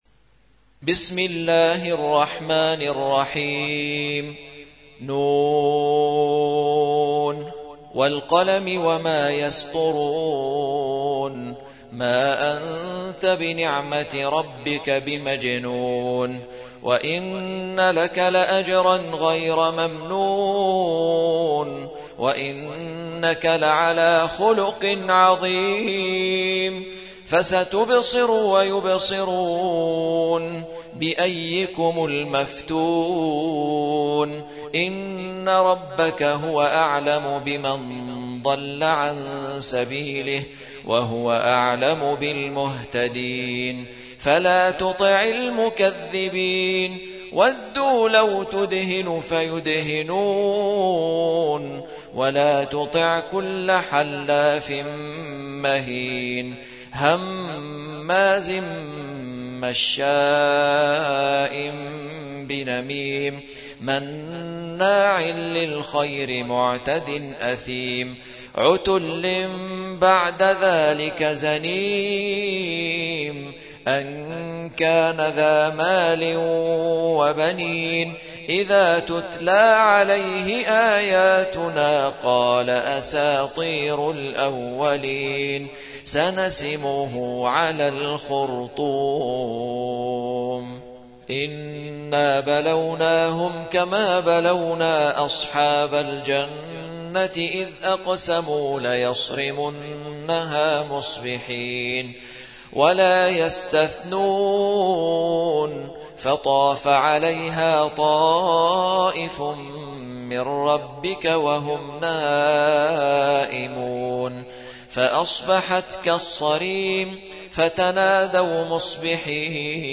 68. سورة القلم / القارئ